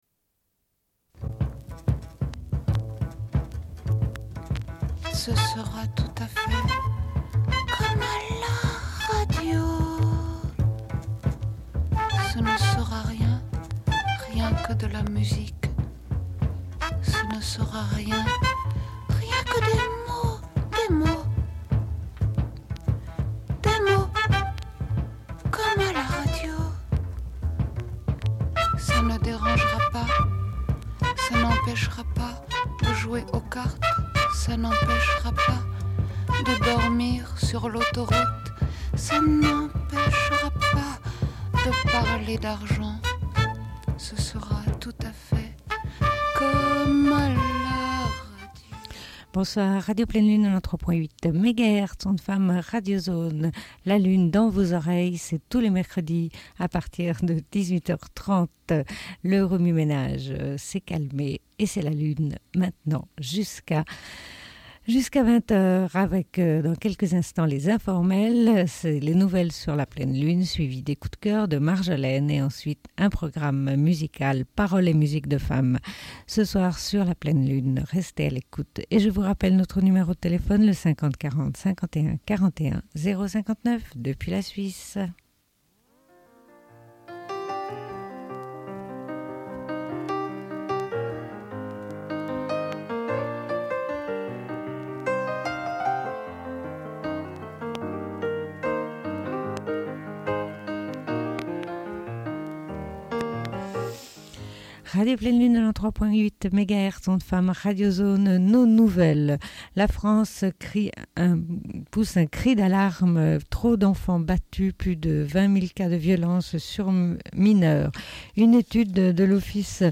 Bulletin d'information de Radio Pleine Lune du 18.09.1996 - Archives contestataires
Une cassette audio, face B